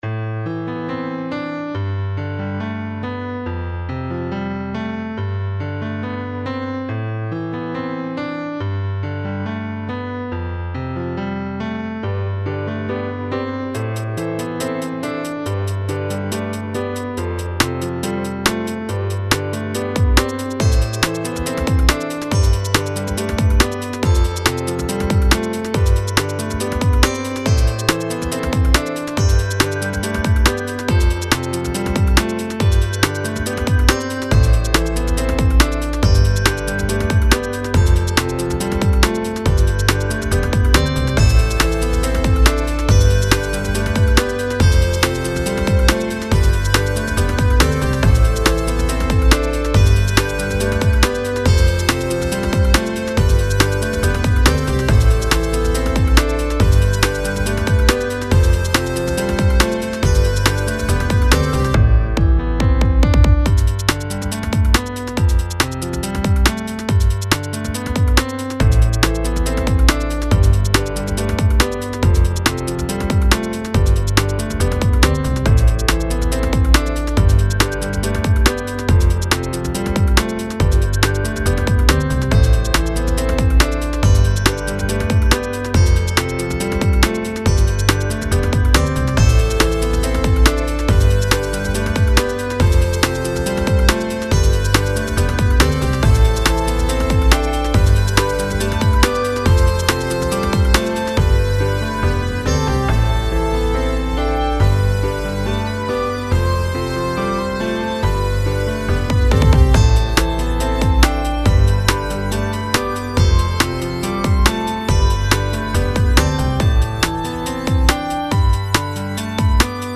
A slight departure from our usual sound, this one has a more drum n bass sound to it and was our first time using Cubase.